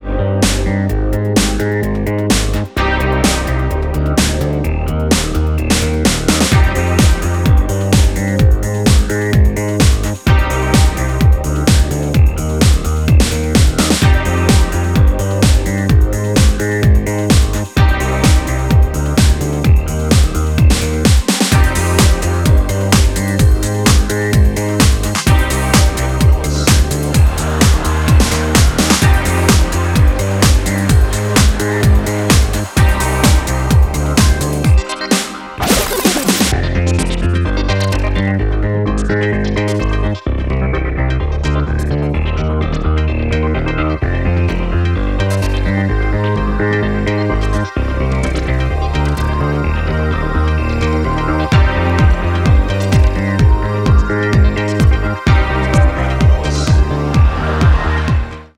ニューウェイヴ影響下の煌びやかなメロディーワークとシンセベースの躍動がレトロ・フューチャーなムードを醸す